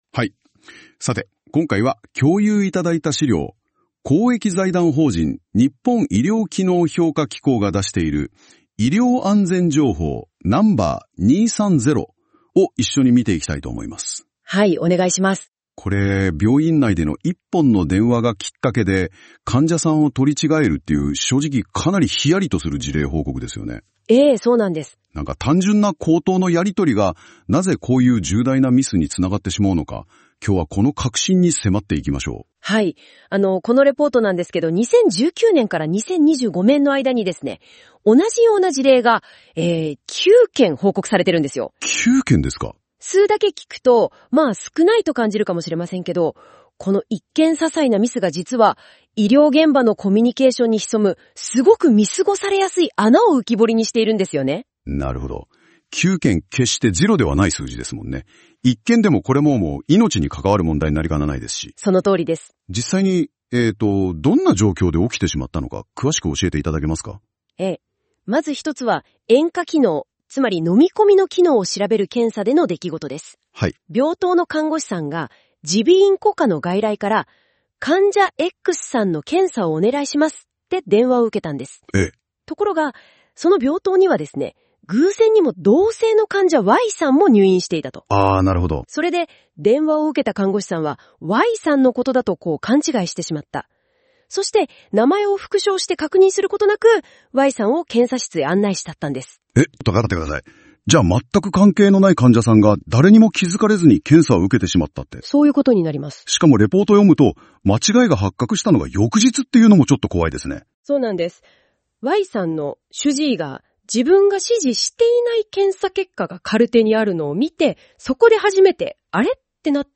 当法人では、医療機能評価機構が発行する「医療安全情報」について、理解を深めていただくための音声解説を配信しております。なお、本ページに掲載している音声は、AIによる自動音声合成で作成しています。そのため、一部に読み方やイントネーションなど不自然に感じられる箇所がありますが、あらかじめご了承ください。